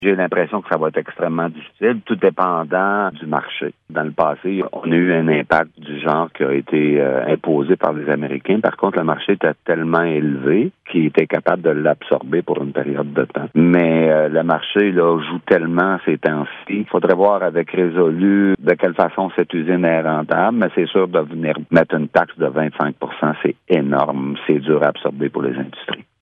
Le préfet de la MRC de Papineau, Benoit Lauzon, affirme qu’une nouvelle augmentation des tarifs douaniers compliquerait la réouverture de la scierie de Domtar :